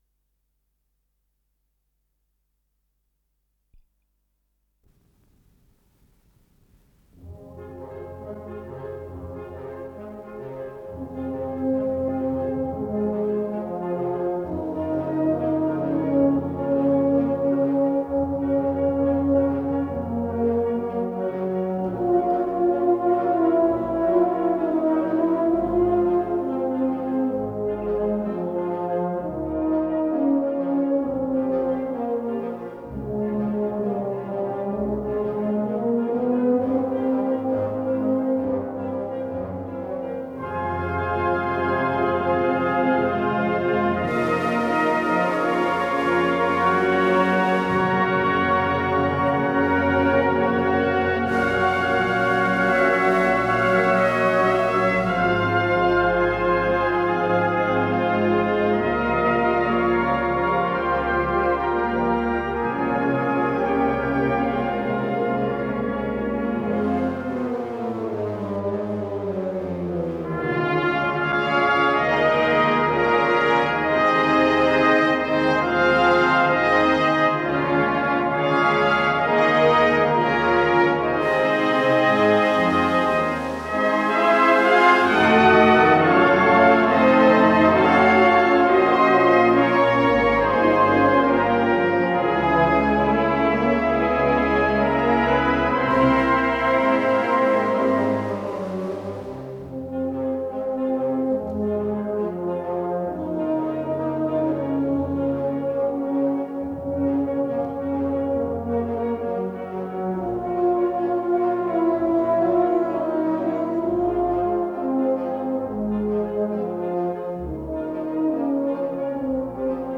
Для духового оркестра